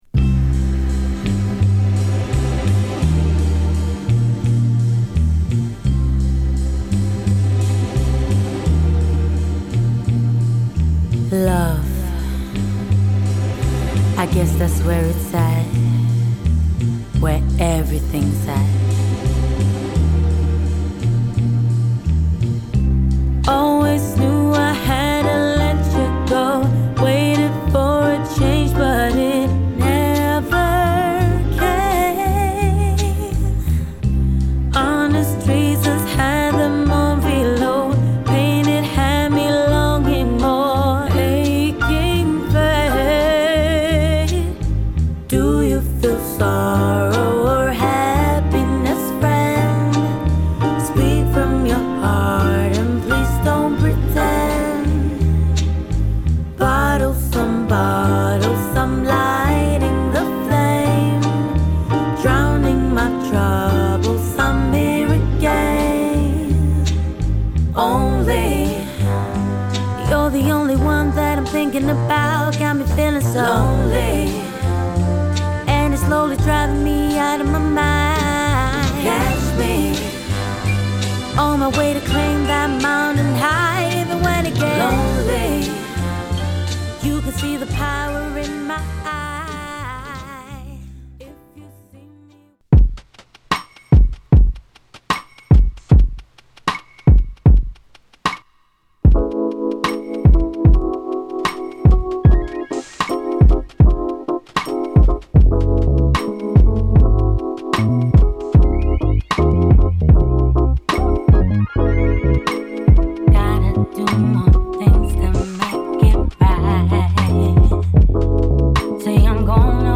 ＊試聴はA1→A3→C1→D1です。